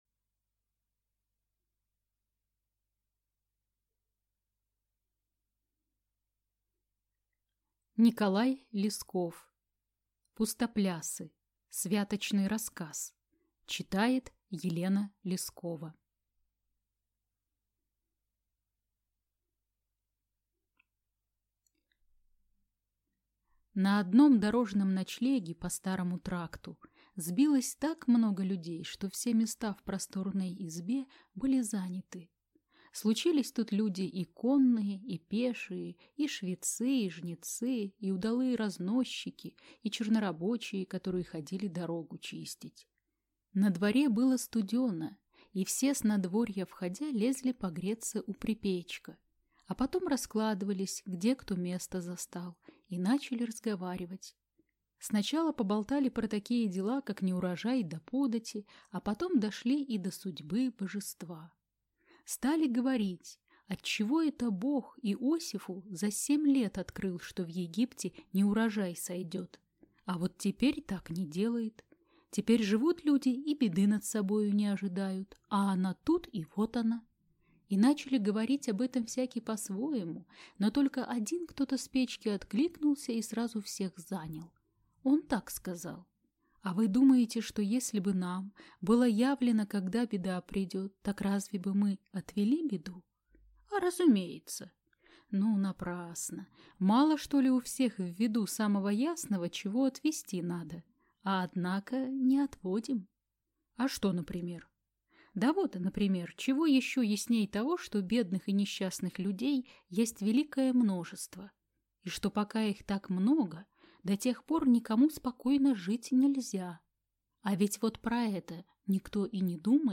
Аудиокнига Пустоплясы | Библиотека аудиокниг